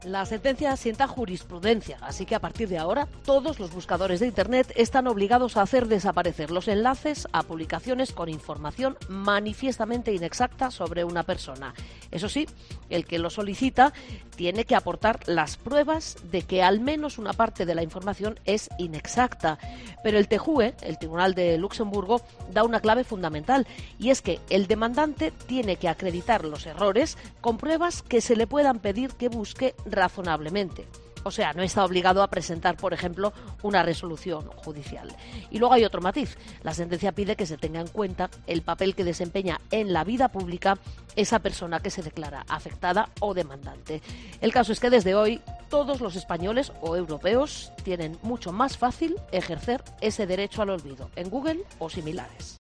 Te cuenta todos los detalles desde Bruselas